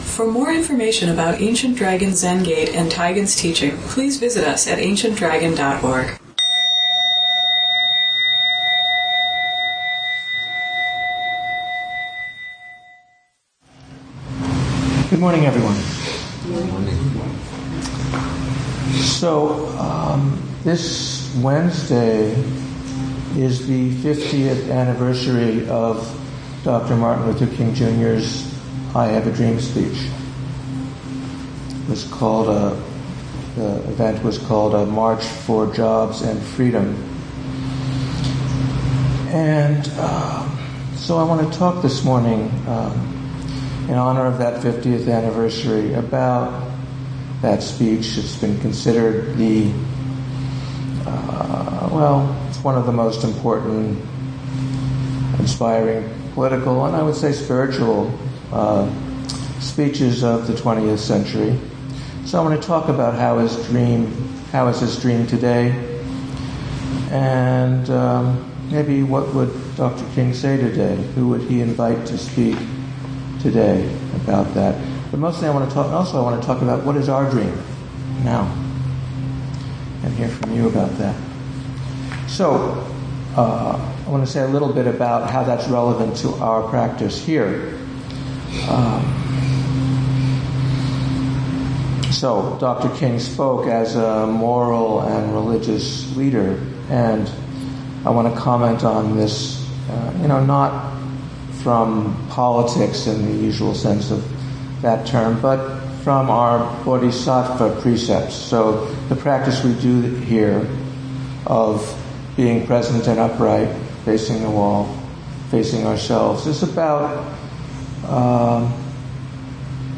ADZG 246 ADZG Sunday Morning Dharma Talk